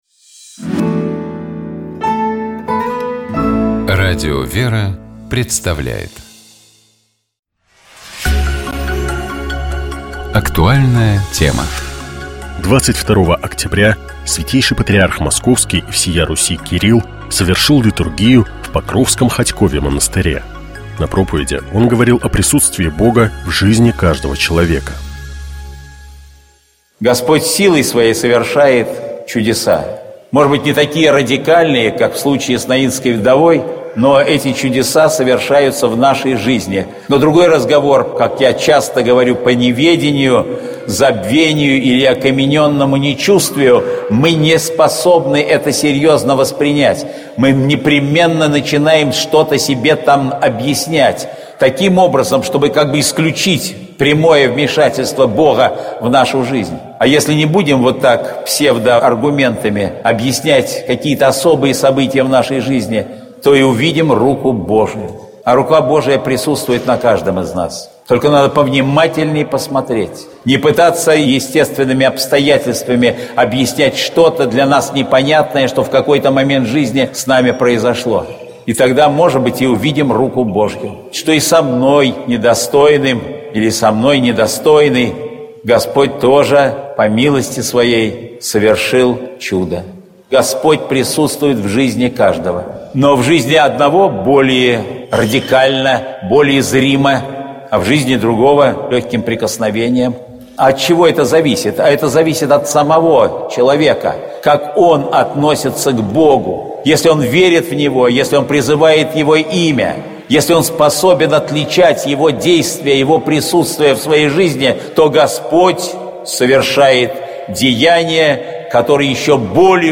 22 октября Святейший патриарх Московский и всея Руси Кирилл совершил Литургию в Покровском Хотькове монастыре.
На проповеди он говорил о присутствии Бога в жизни каждого человека: